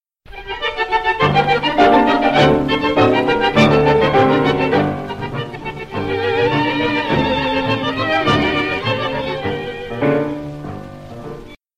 Milonga